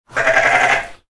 Single Sheep Bleating Call – Natural Farm Sound Effect
A clean, isolated recording of a single sheep vocalization. This “baa” sound is crisp and authentic, making it a perfect fit for farm-themed transitions, educational apps, or adding organic life to rural soundscapes.
Single-sheep-bleating-call-natural-farm-sound-effect.mp3